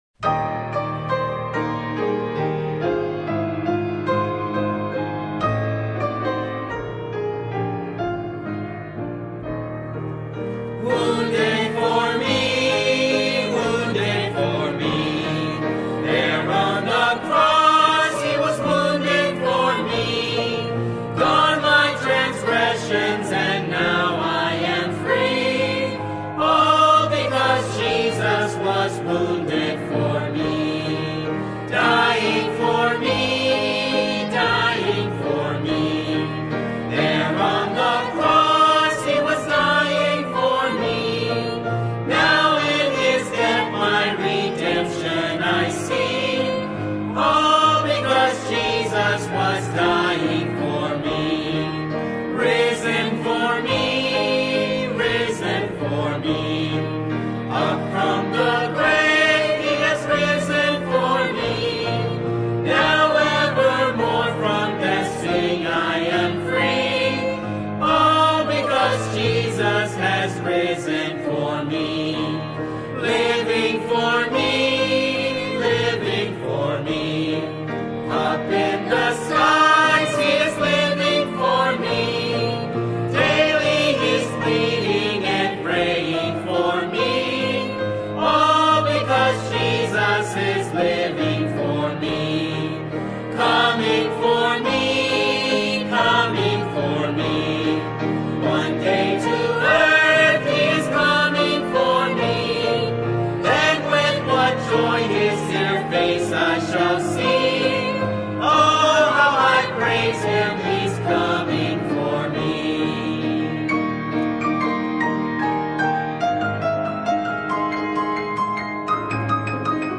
Music Hymns